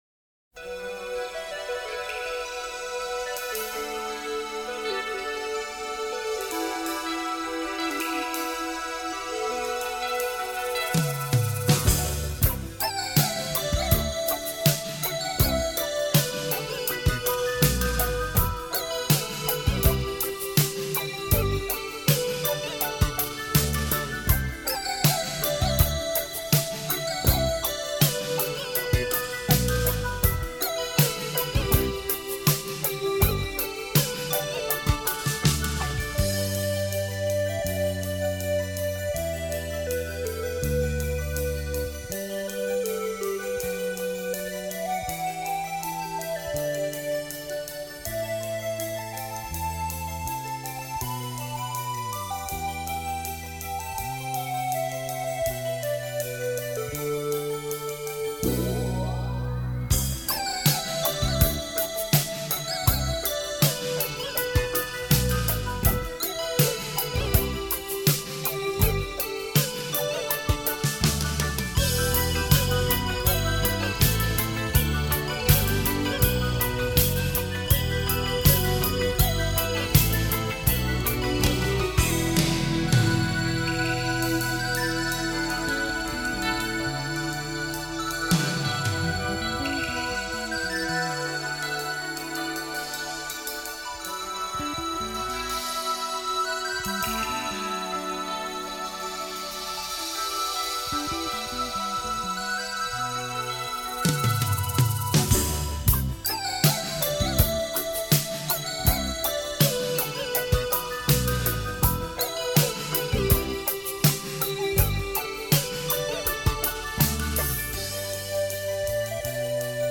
他的音乐，旋律优美动人，抒情感性。